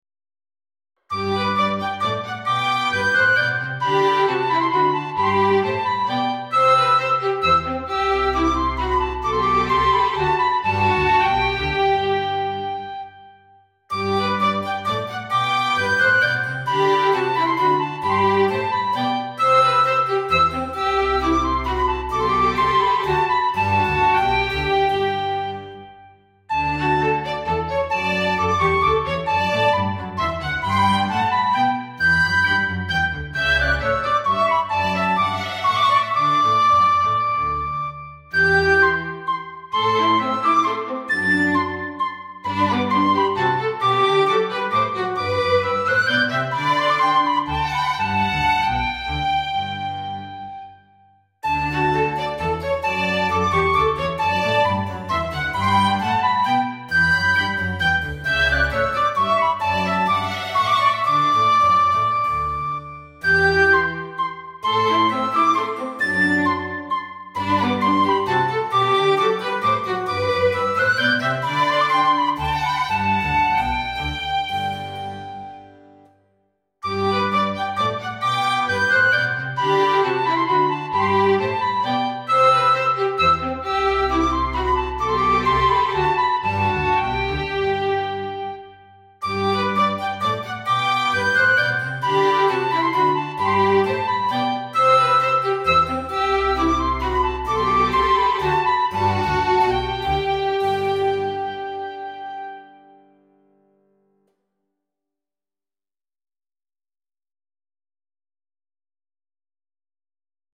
all created with samples
Most of the pieces are played between 2002 and 2014 and also mixed as it was normal at that time...